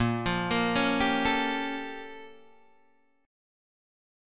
A# Minor Major 13th